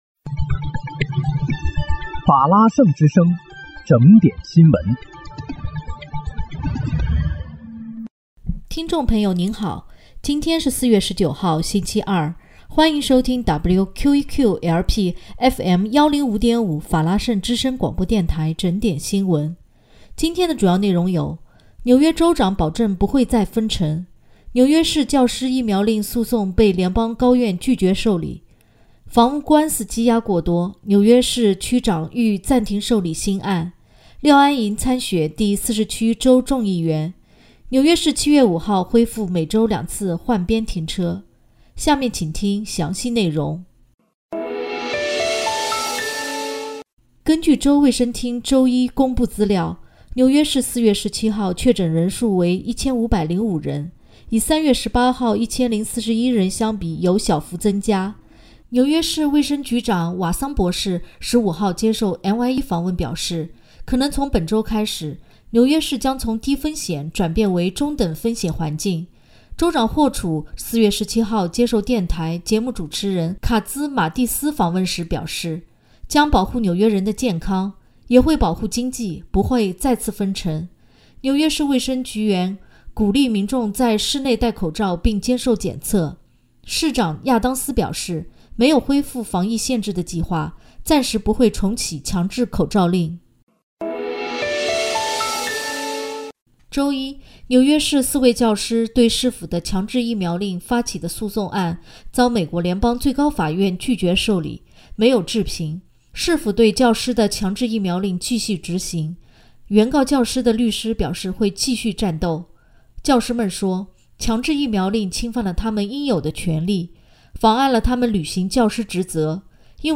4月19日（星期二）纽约整点新闻